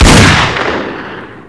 sol_reklam_link sag_reklam_link Warrock Oyun Dosyalar� Ana Sayfa > Sound > Weapons > Mag Dosya Ad� Boyutu Son D�zenleme ..
WR_fire.wav